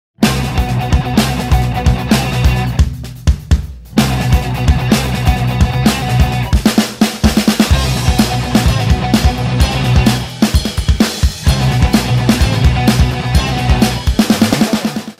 • Качество: 192, Stereo
Это проигрыш из песни